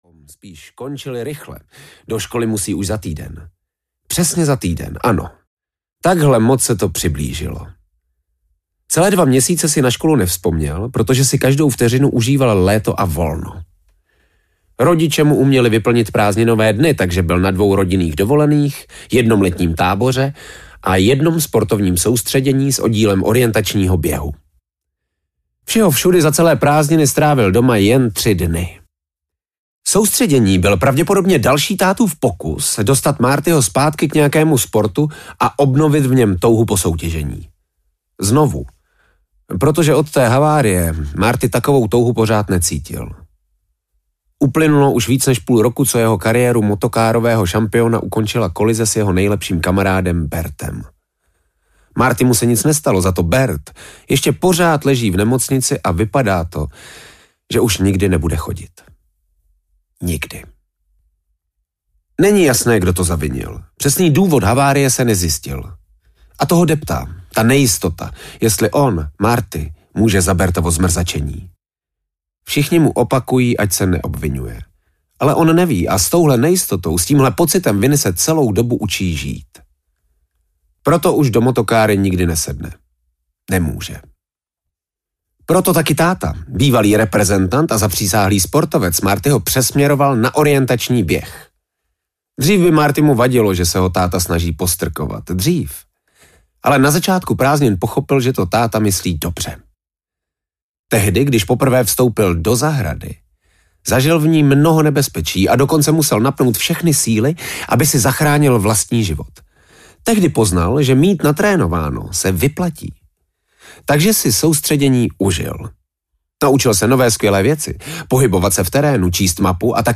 Zahrada a hlasy z temnoty audiokniha
Ukázka z knihy